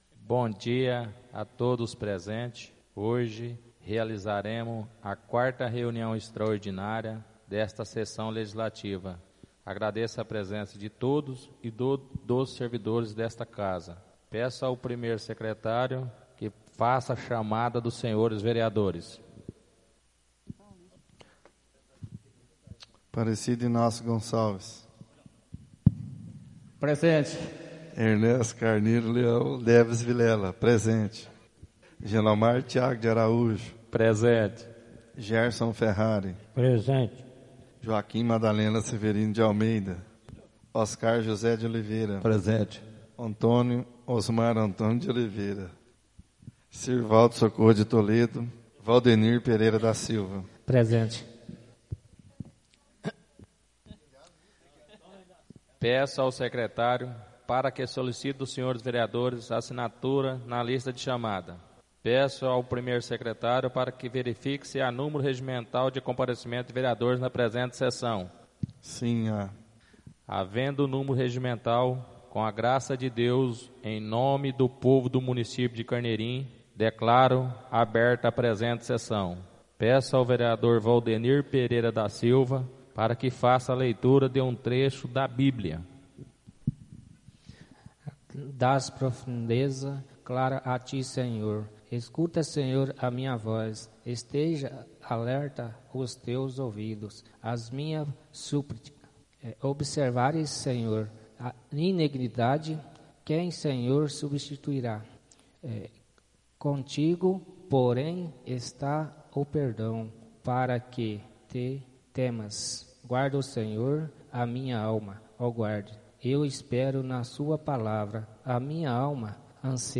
Áudio da 4ª sessão extraordinária de 2016, realizada no dia 07 de Junho de 2016, na sala de sessões da Câmara Municipal de Carneirinho, Estado de Minas Gerais.